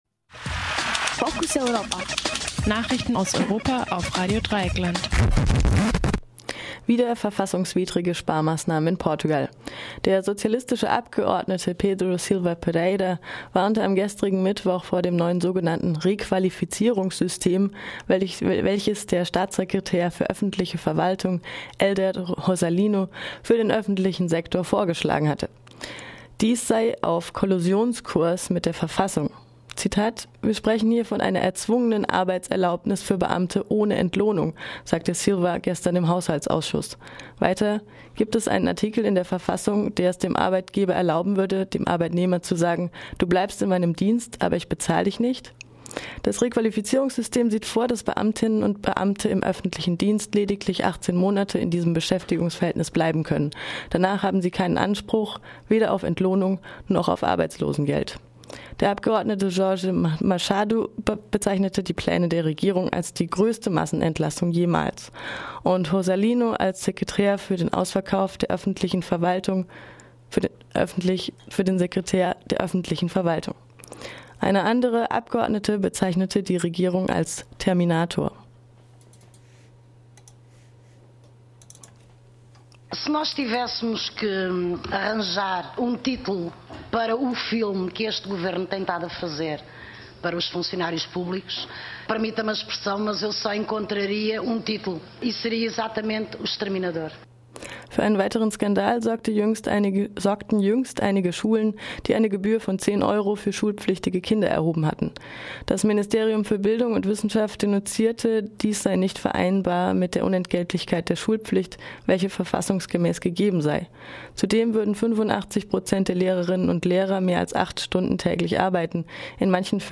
Focus Europa Nachrichten am Donnerstag, den 9. Mai - 9:30 Uhr